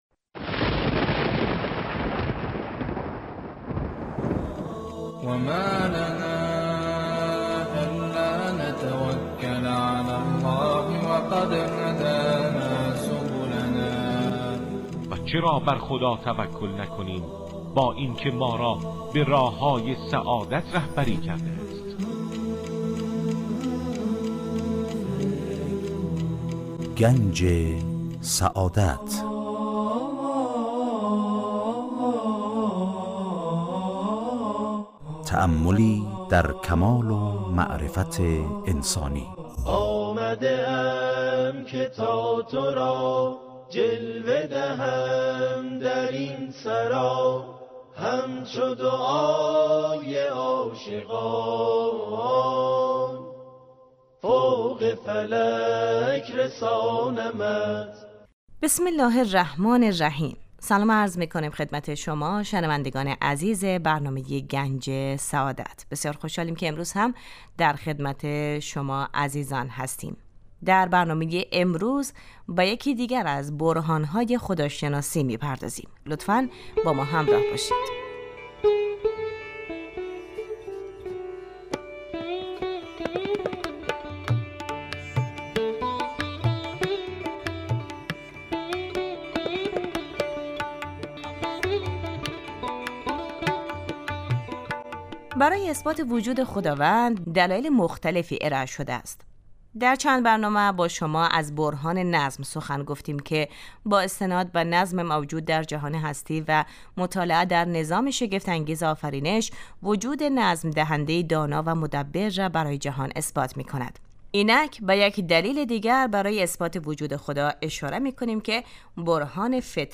در این برنامه سعی می کنیم موضوعاتی همچون ؛ آفرینش ، یکتاپرستی و آثار و فواید آن، همچنین فلسفه و اهداف ظهور پیامبران را از منظر اسلام مورد بررسی قرار می دهیم. موضوعاتی نظیر عدل خداوند، معاد و امامت از دیگر مباحثی است که در این مجموعه به آنها پرداخته می شود این برنامه هر روز به جزء جمعه ها حوالی ساعت 12:35 از رادیودری پخش می شود.